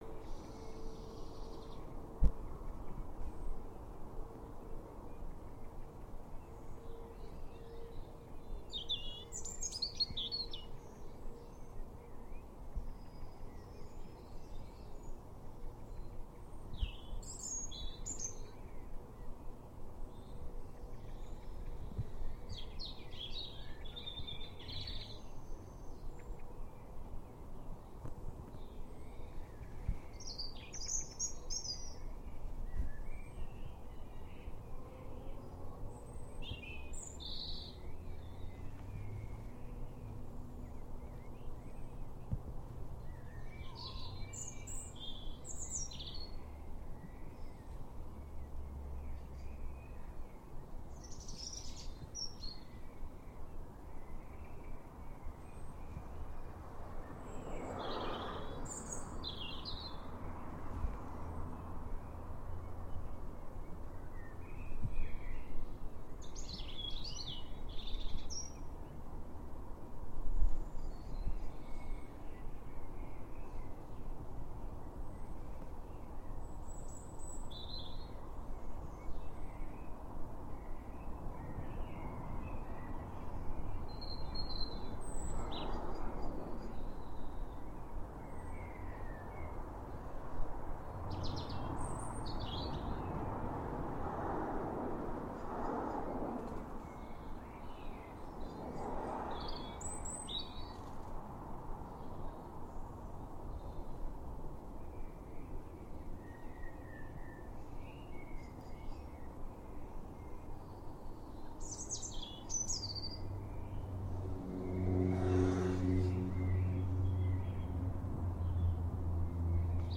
May birds at dusk. Includes close birdsong and wing flaps